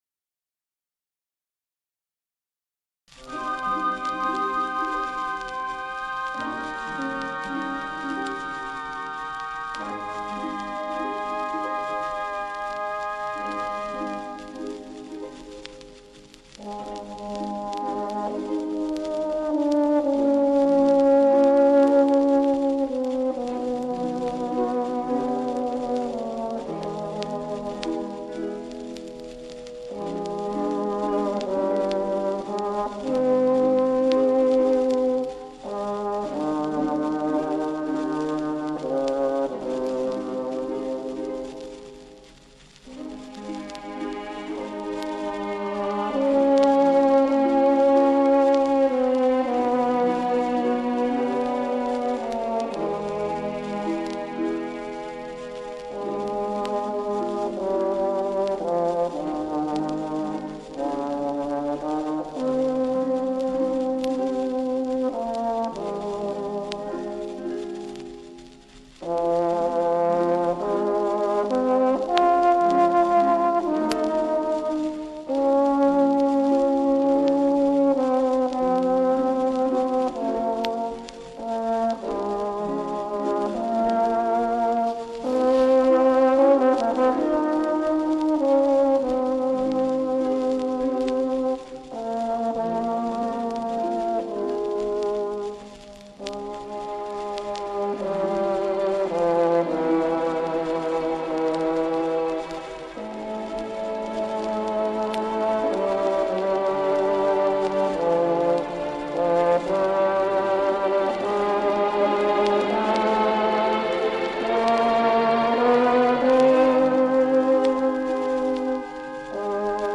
2. Ideal dynamic range plus clarity and brilliance.